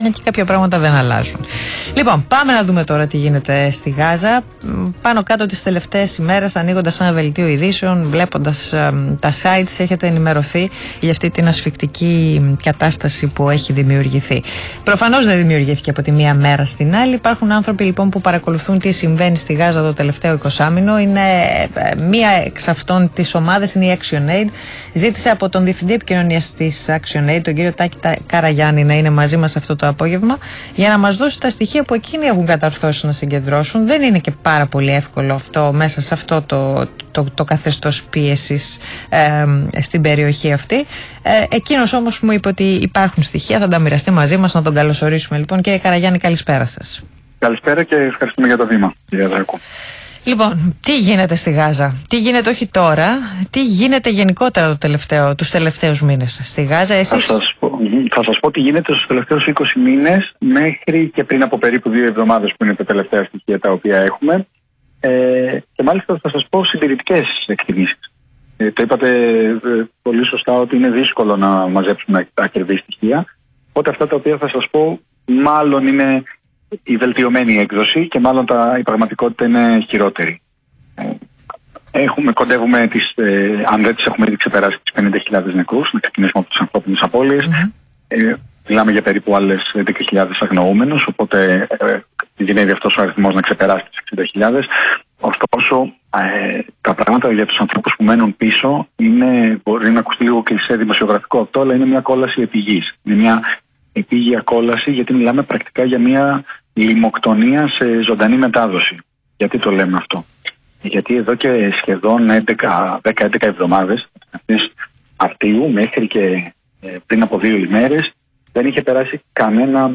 Συνέντευξη στον ΣΚΑΪ 100,3: Αποκλεισμός πρόσβασης ανθρωπιστικής βοήθειας στη Γάζα